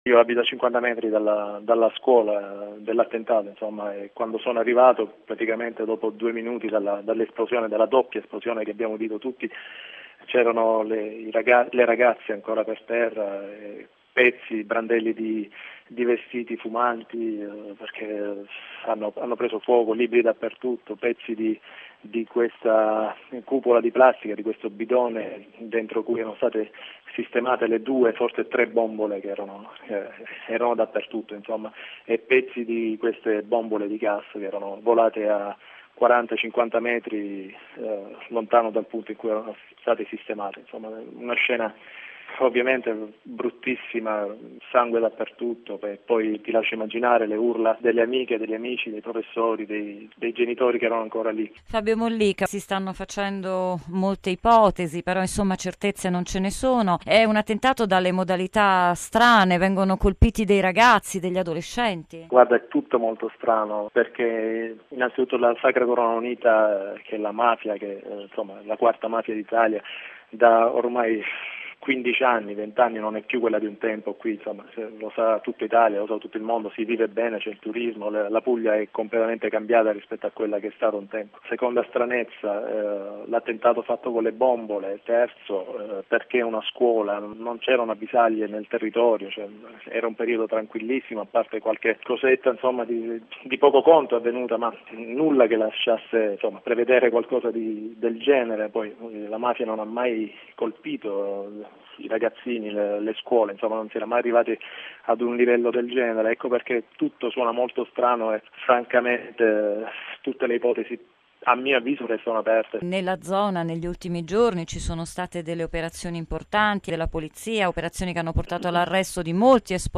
ecco ora la testimonianza di un testimone oculare dell’attentato